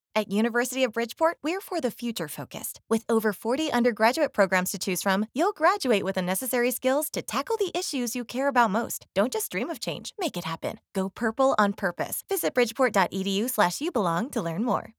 Silver award: Radio Advertising
radio-advertising.mp3